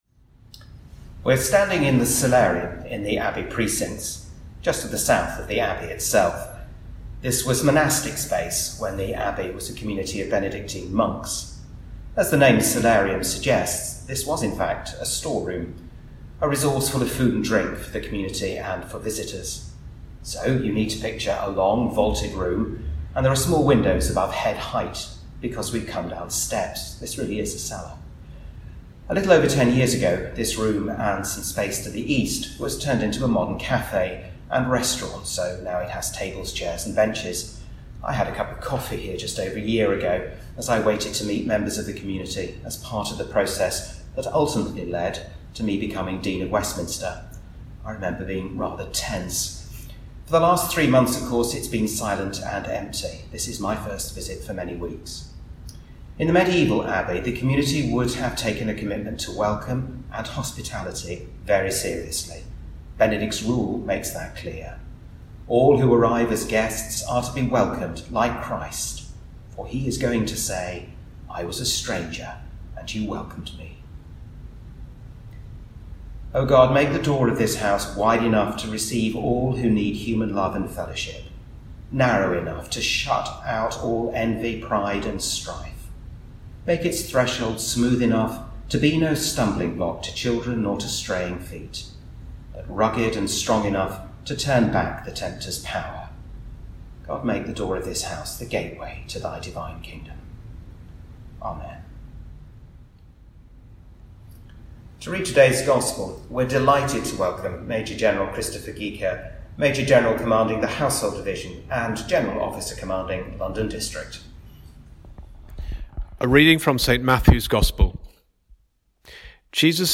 Recorded in the monastic ‘Cellarium’, which is now a café, this podcast considers themes of welcome and hospitality, as lockdown begins to ease, and places of hospitality begin to re-open....
A 20-minute service of prayers, reading, address, music and blessing recorded this week.
This podcast was recorded with all contributors observing social distancing.